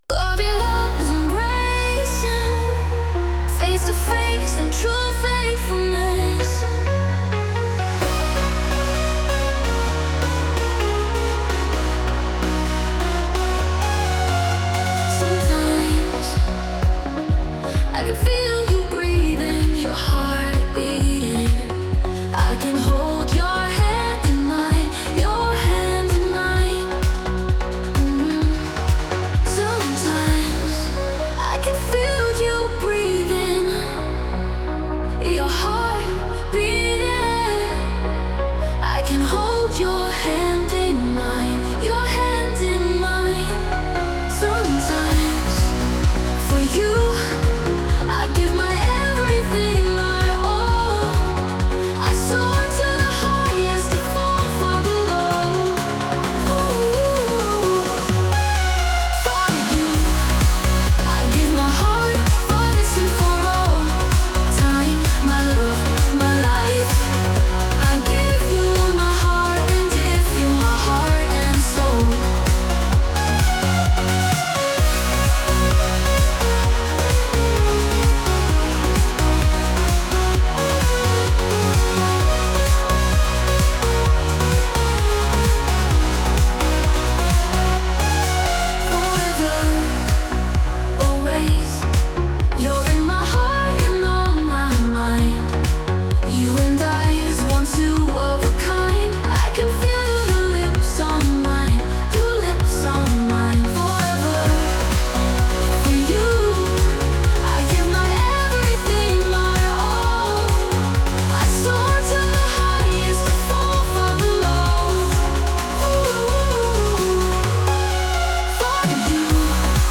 Embrace this trance track and find your own melody!......